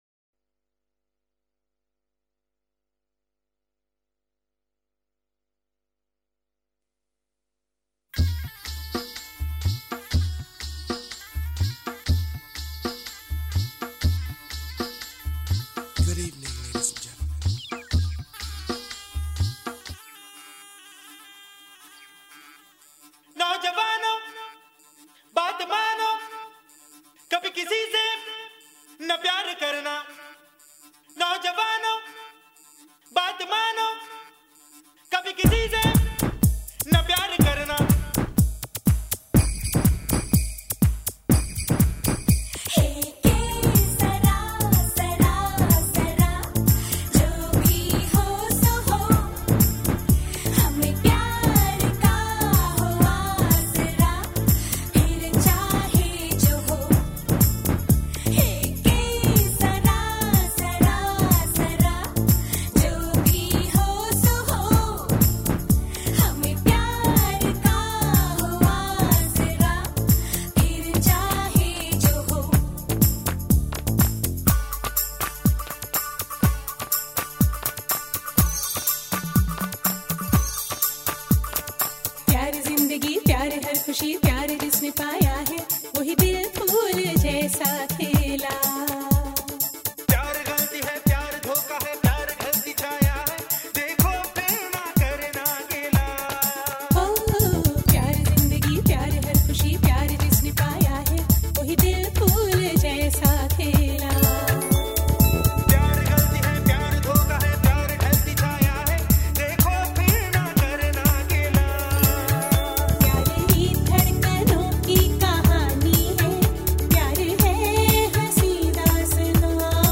Genre: Soundtracks & Musicals.